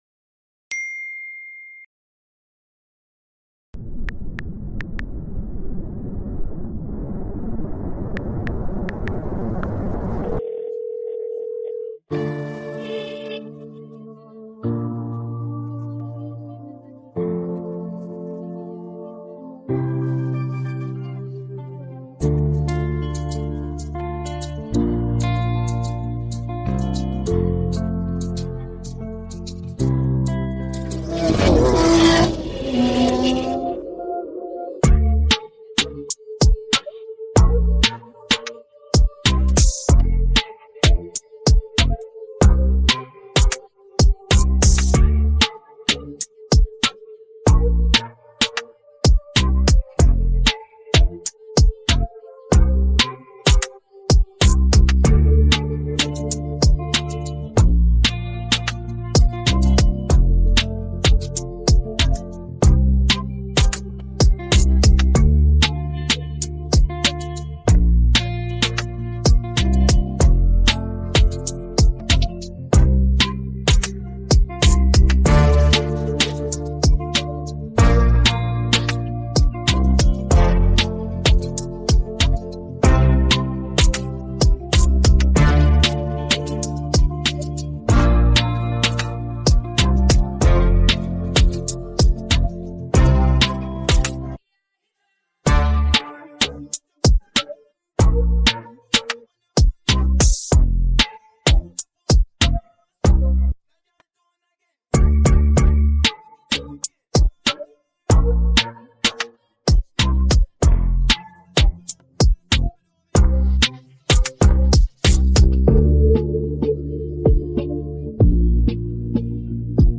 Dancehall
instrumental